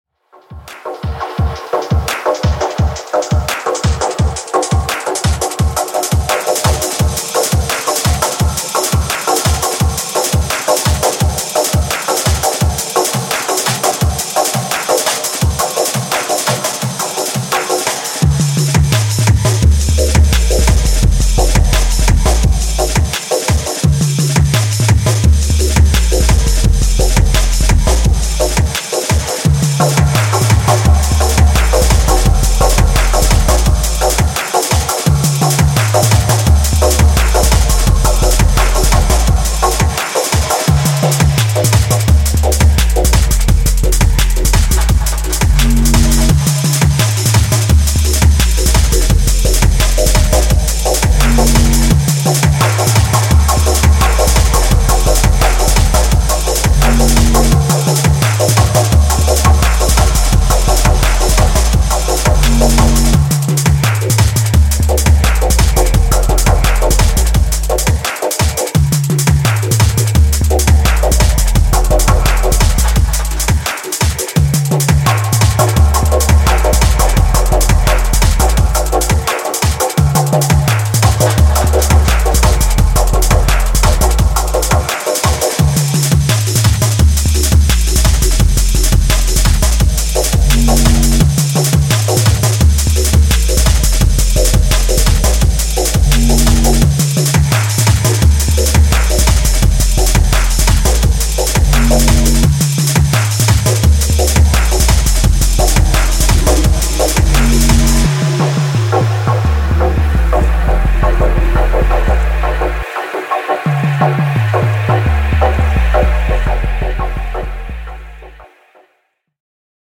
Styl: Dub/Dubstep, Drum'n'bass